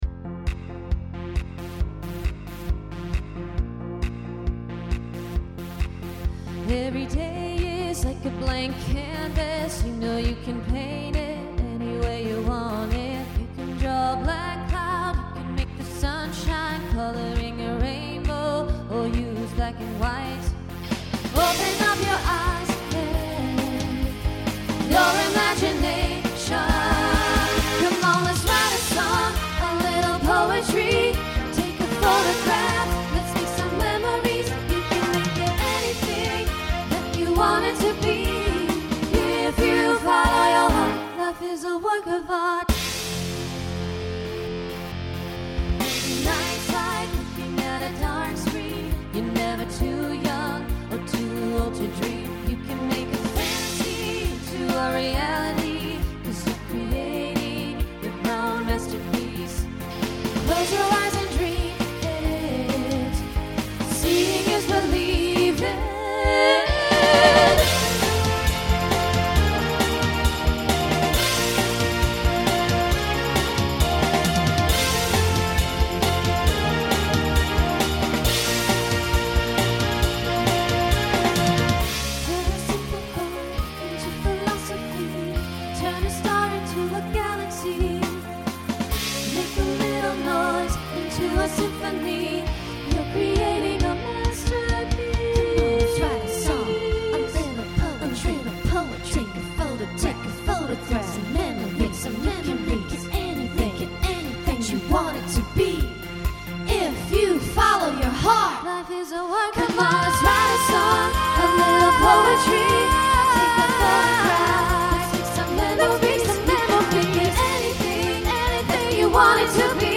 New SAB voicing for 2025.